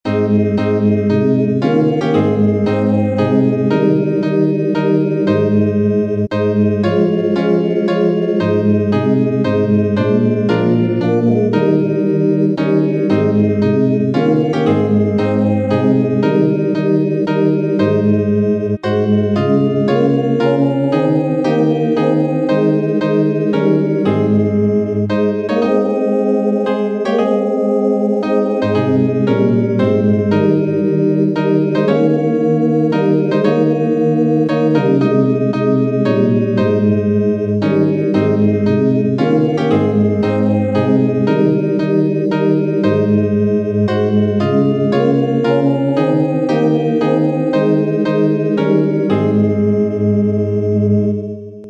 Patronal hymn, Parish Church of St Nicholas, Chiswick (London), England.